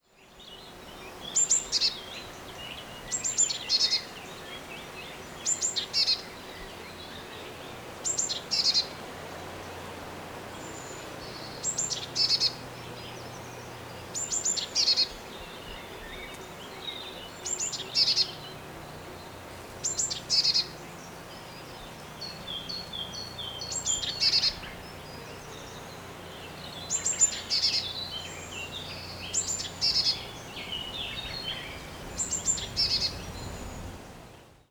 Herrerillo (Cyanistes caeruleus)
El pequeño herrerillo es un párido que presenta un amplio repertorio.
Alarma: